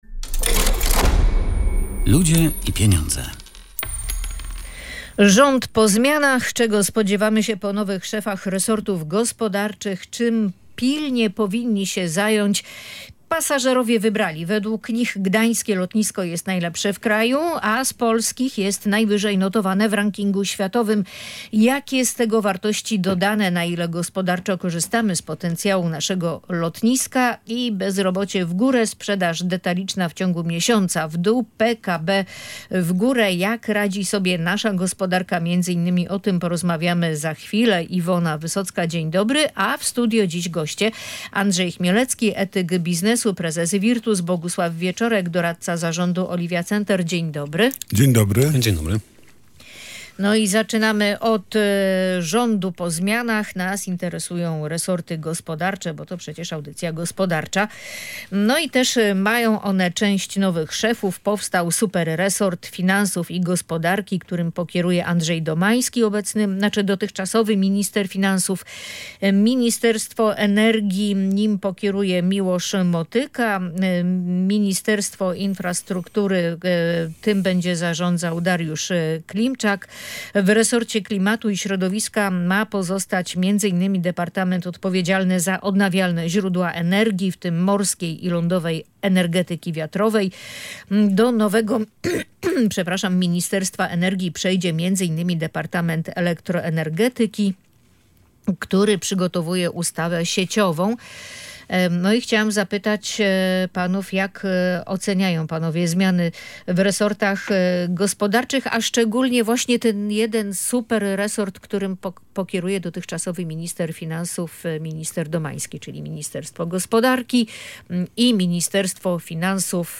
Na ten temat dyskutowaliśmy w audycji „Ludzie i Pieniądze”.